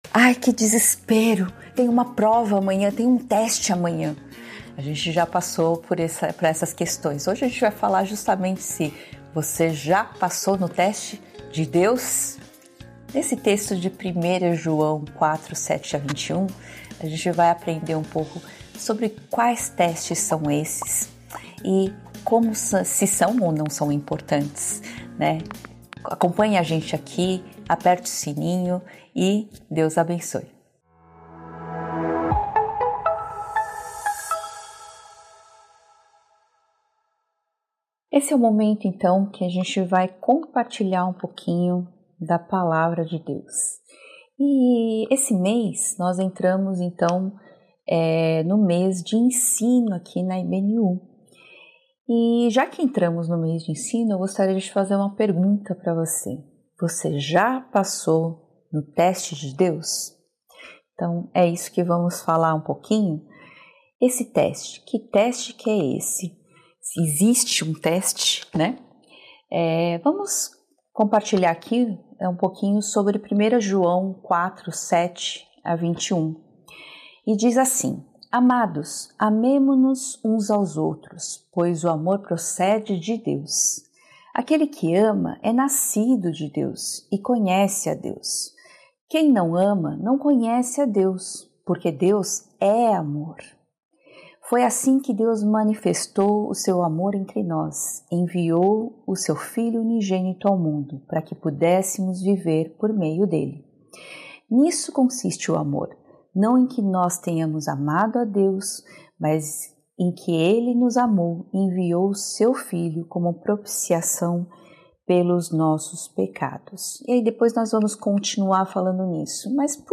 Mensagem
Igreja Batista Nações Unidas